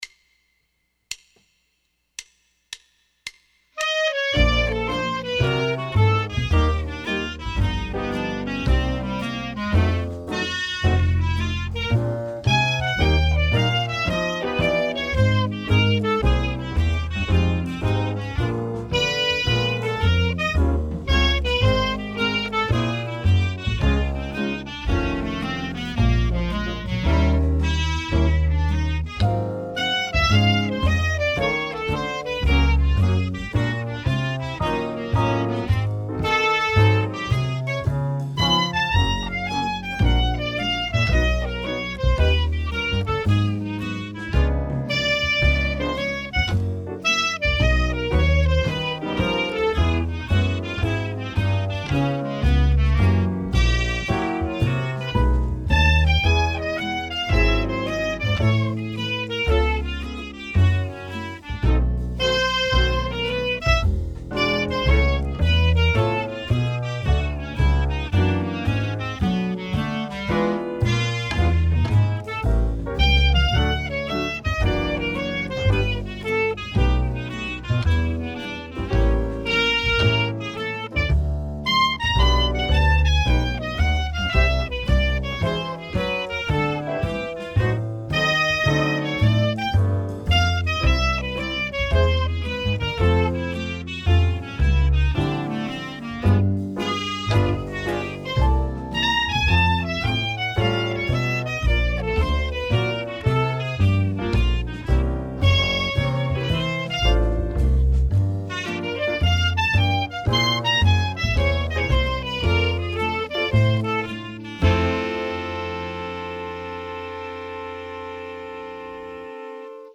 Below is a recording of the pattern in all 12 keys.
What you hear in this recording is actually 5 clarinets.  Took me about 20 takes to get 5 usable ones.